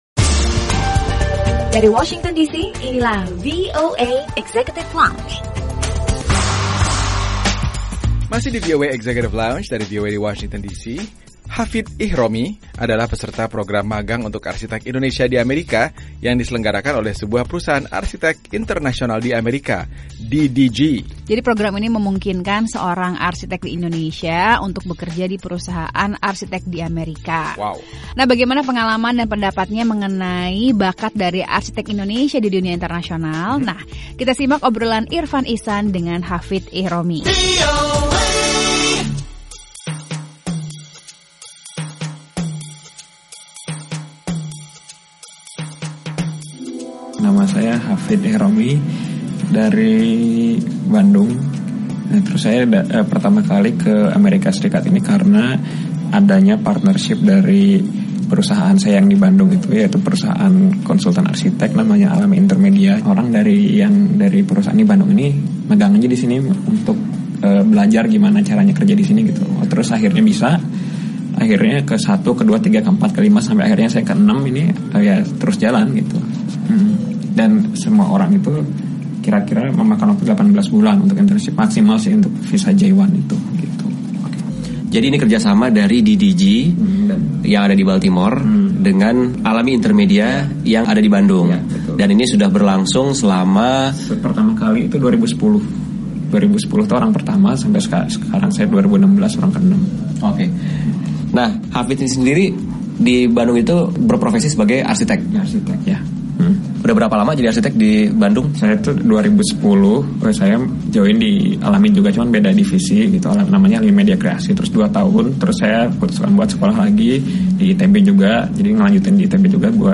Obrolan bersama warga Indonesia